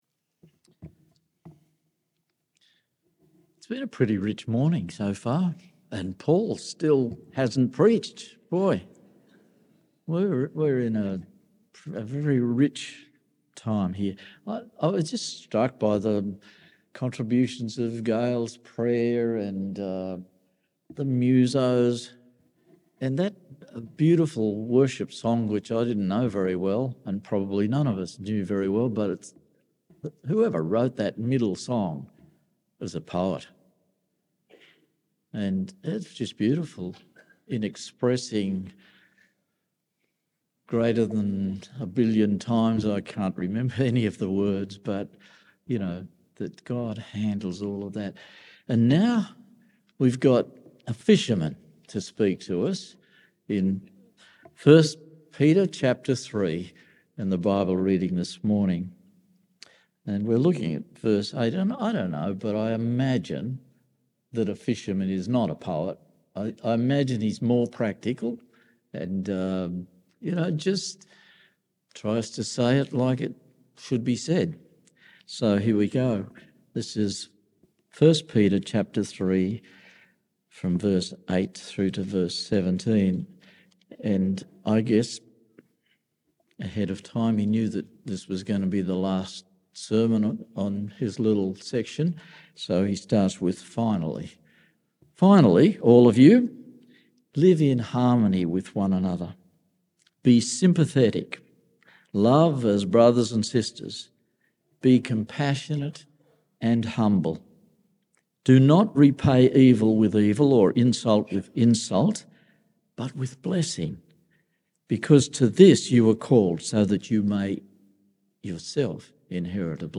September Sermons